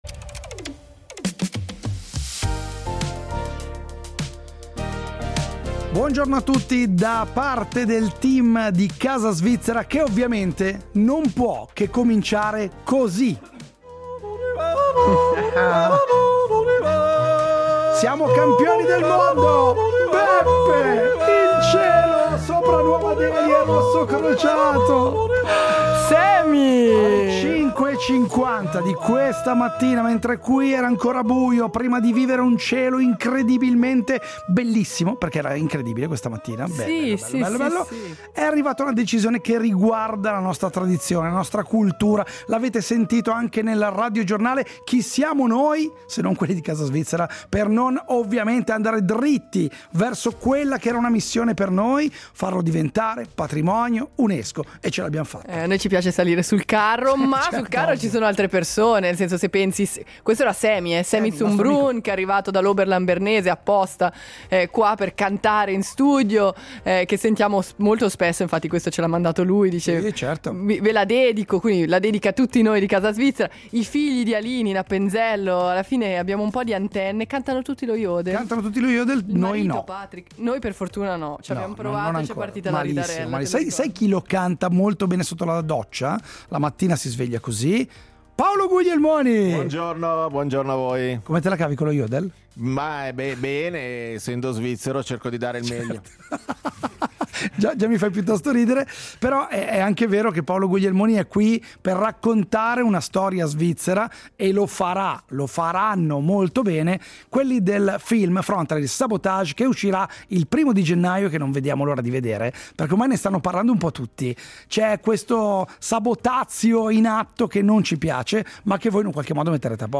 E da qui a Natale vi faremo ascoltare uno jodel diverso ogni giorno, dalla Svizzera… e dal mondo.